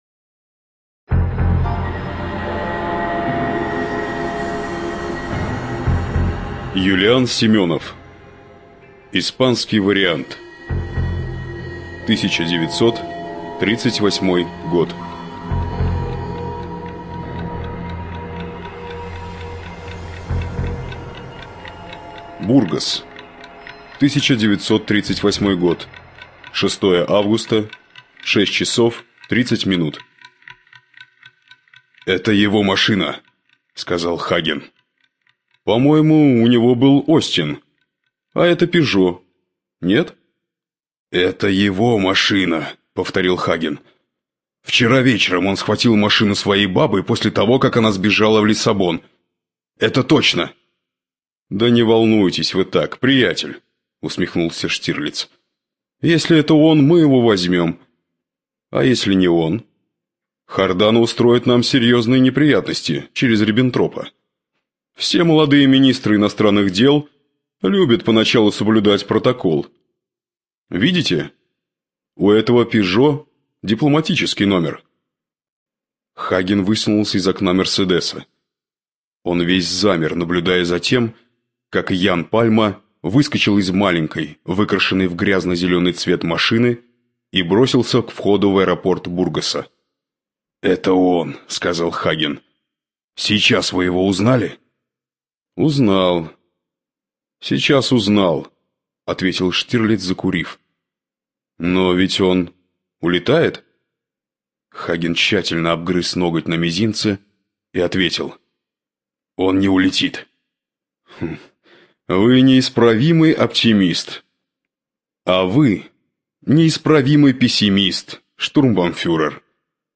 ЖанрДетективы и триллеры
Студия звукозаписиРавновесие